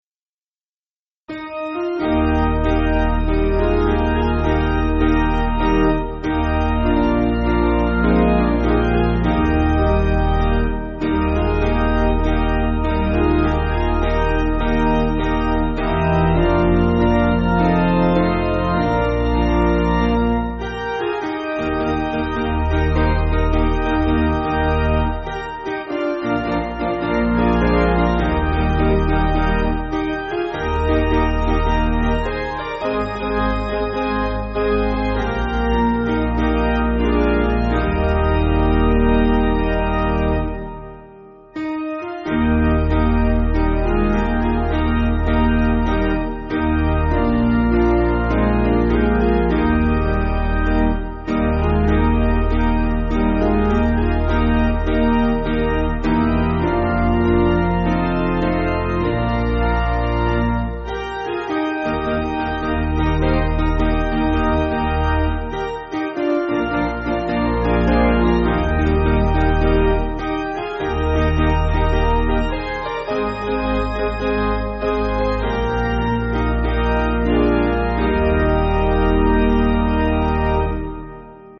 Basic Piano & Organ
(CM)   5/Eb